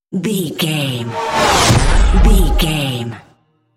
Whoosh speed with shot
Sound Effects
dark
intense
whoosh